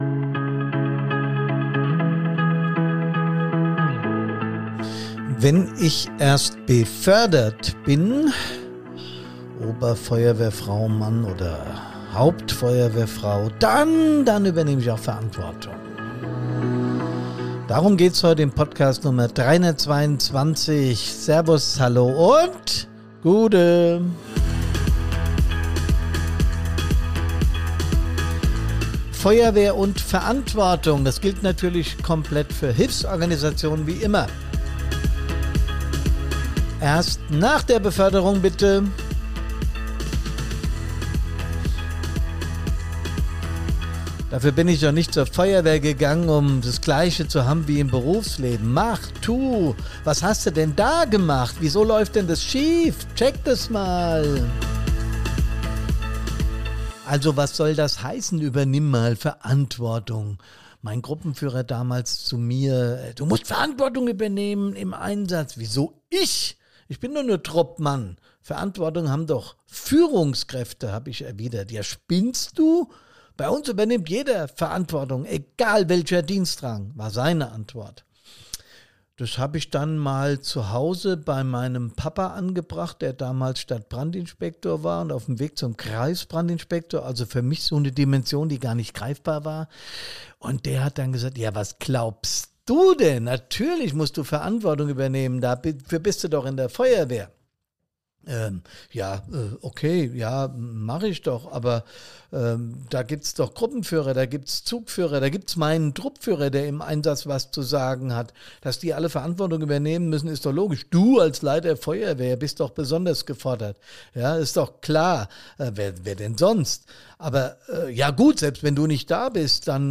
Mit Servus, Hallo und Guuude, begrüßt euch der gebürtige Hesse